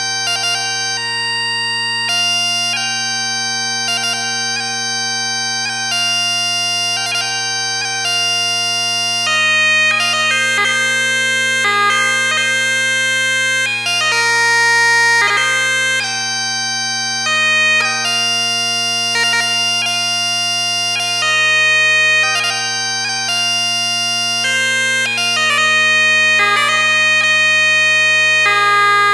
スコアをクリックすると 1st ラインを Blair Digital Chanter で 演奏した音源が流れます。